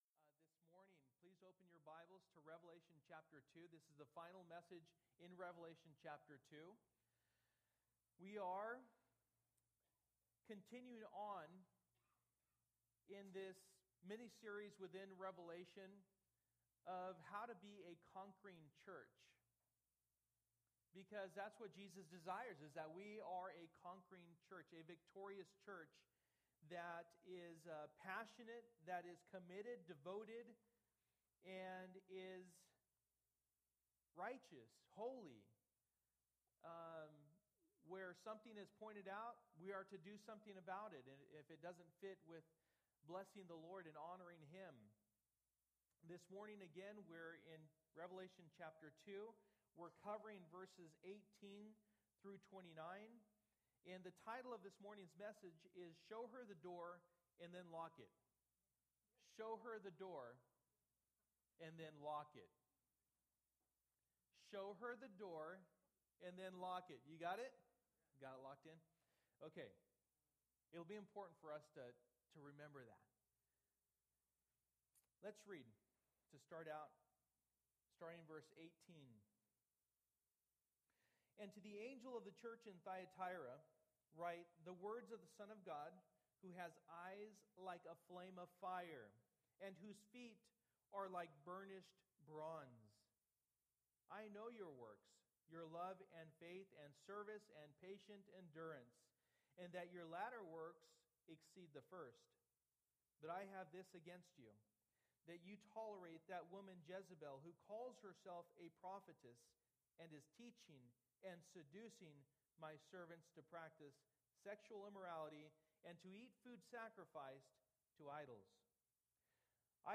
Passage: Revelation 2:18-29 Service: Sunday Morning